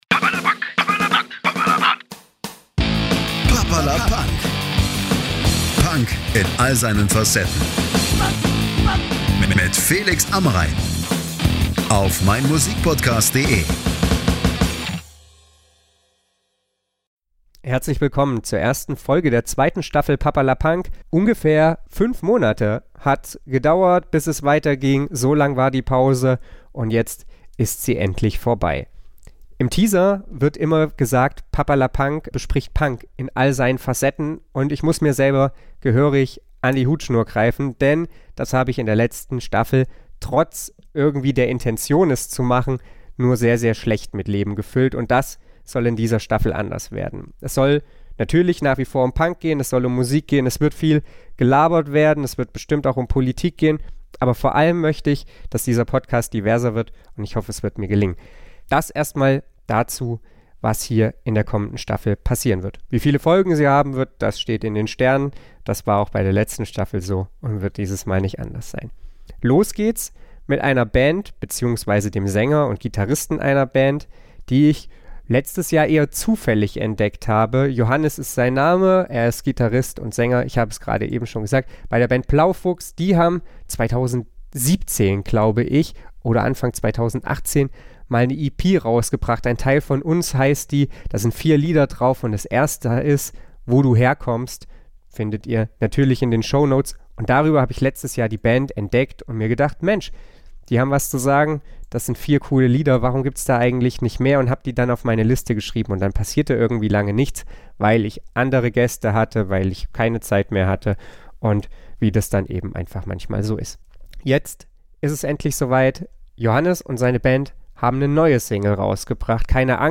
Den Podcast haben wir am 07.09.2021 via StudioLink aufgenommen.